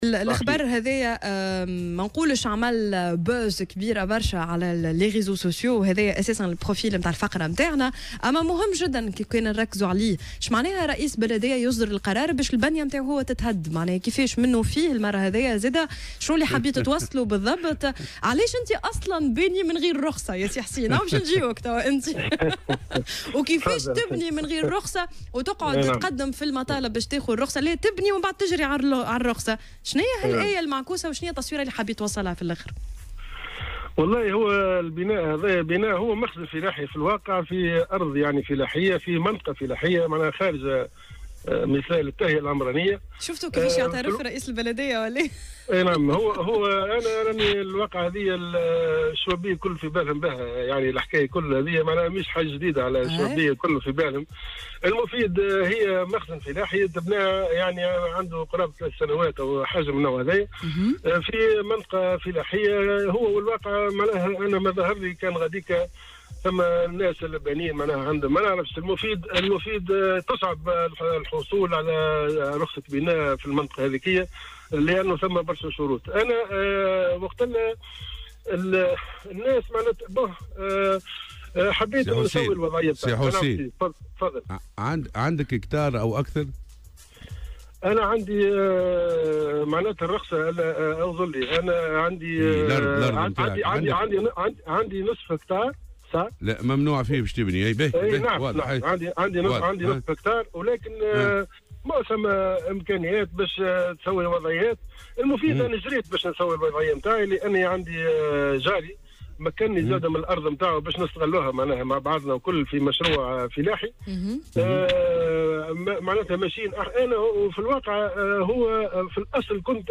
وقال النصري في مداخلة له اليوم في برنامج "صباح الورد" على "الجوهرة أف أم" إن البناية شيّدت على أرض فلاحية، خارج مسالك التهيئة العمرانية وإنه سعى إلى تسوية وضعيته بعد حصوله على موافقة مبدئية من المندوبية الجهوية للفلاحة بالجهة بهدف إحداث مشروع فلاحي.